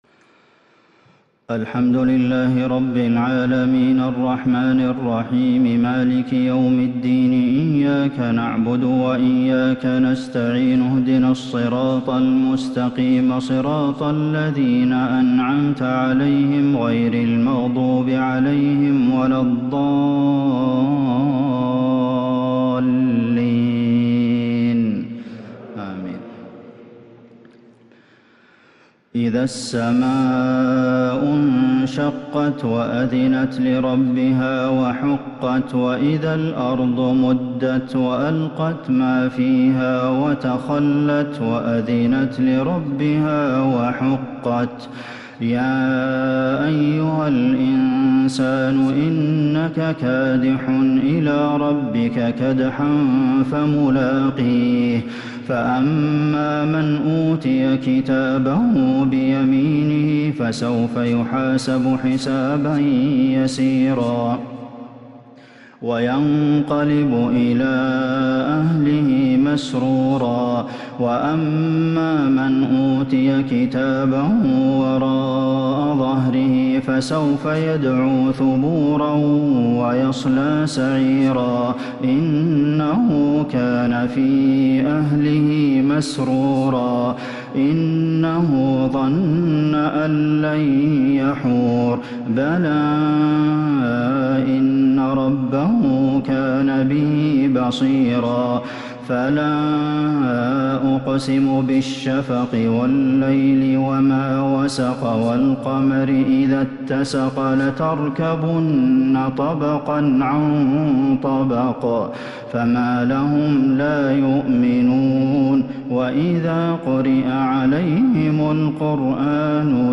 صلاة التهجد l ليلة 29 رمضان 1442ھ l من سورة الانشقاق إلى سورة الشمس | tahajud prayer The 29rd night of Ramadan 1442H | > تراويح الحرم النبوي عام 1442 🕌 > التراويح - تلاوات الحرمين